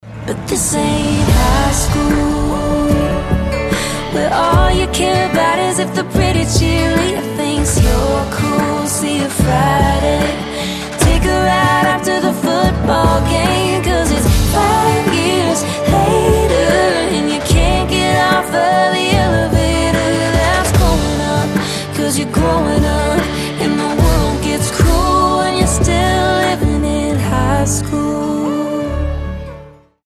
поп
грустные
красивый женский вокал
лиричные